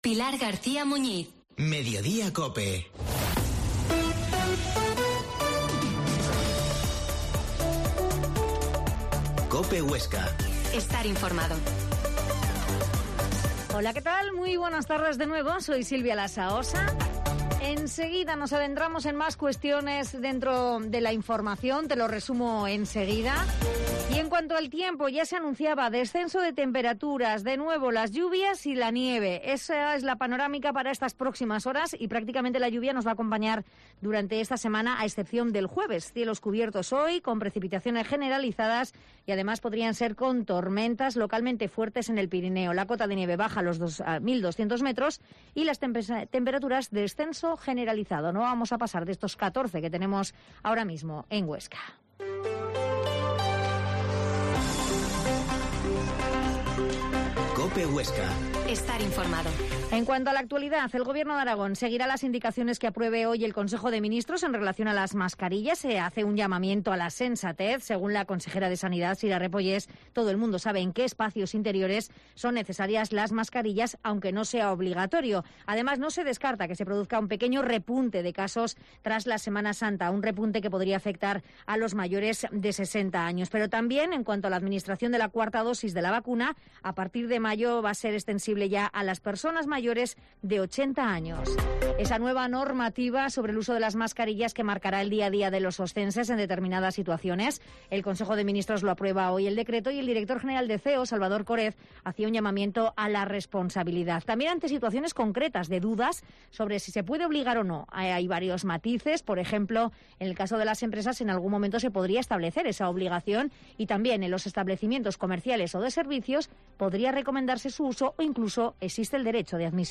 Mediodia en COPE Huesca 13.50h Entrevista a alcalde de Gurrea de Gállego, Carlos Til